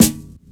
• Steel Snare Drum Sound A Key 60.wav
Royality free acoustic snare sound tuned to the A note.
steel-snare-drum-sound-a-key-60-CF6.wav